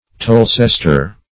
Search Result for " tolsester" : The Collaborative International Dictionary of English v.0.48: Tolsester \Tol"ses*ter\, n. [LL. tolsestrum.